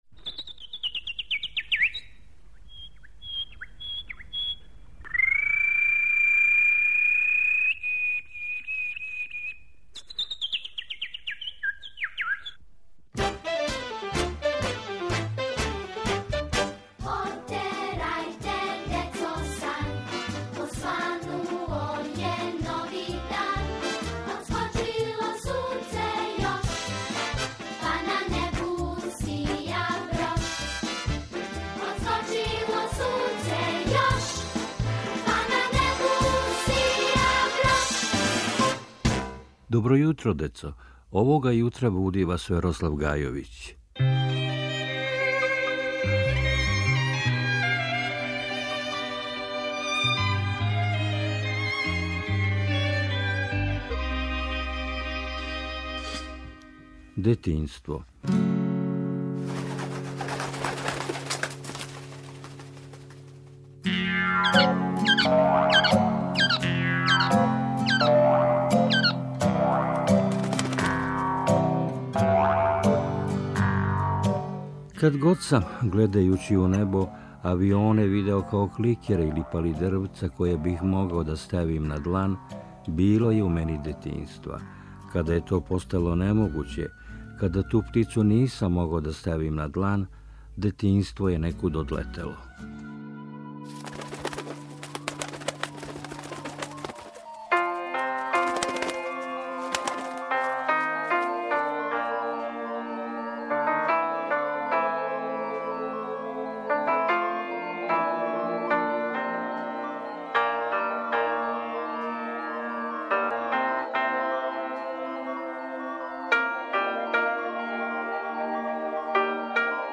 Поезија